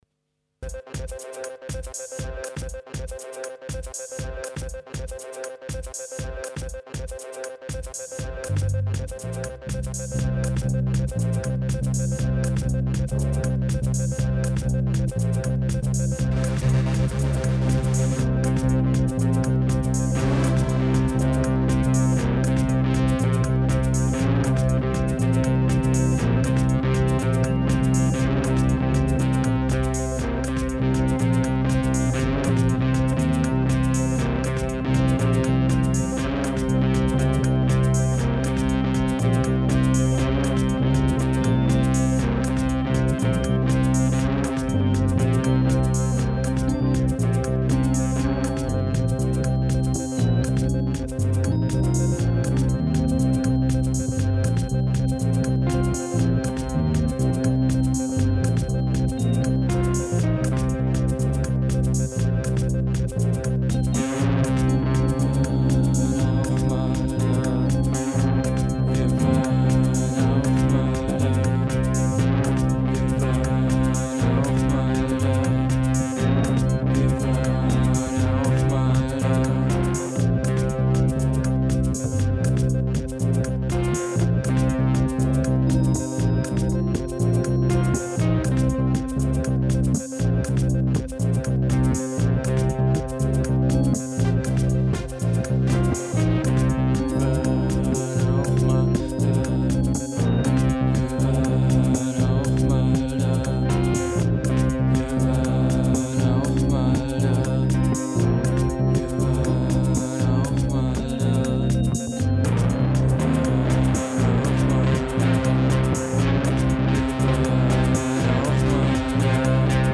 Bizarre Scifi Kulisse.